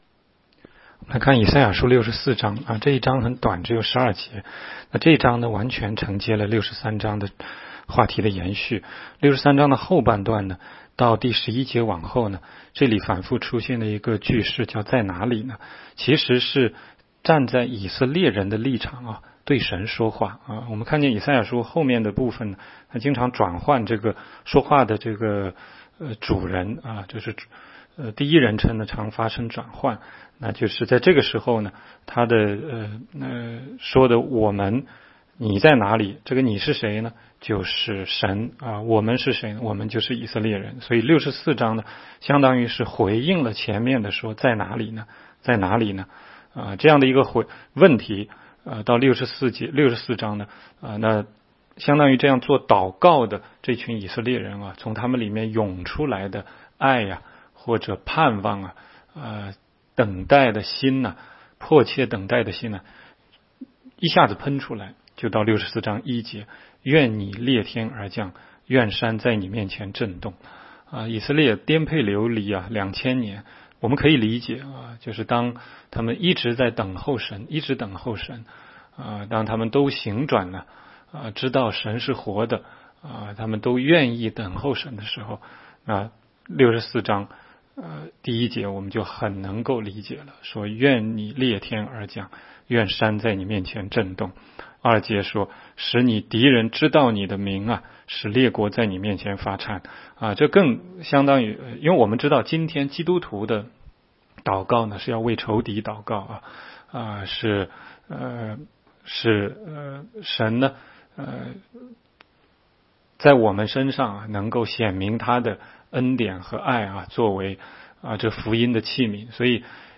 16街讲道录音 - 每日读经 -《以赛亚书》64章